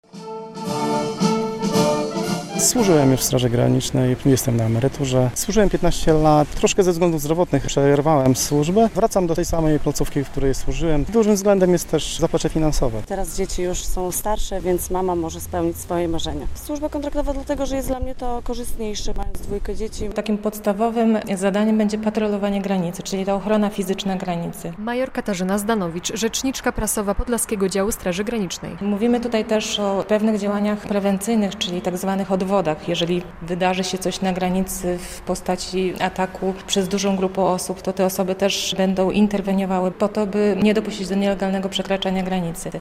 To pierwsze ślubowanie osób przyjętych do Straży Granicznej w ramach nowego rodzaju służby, służby kontraktowej.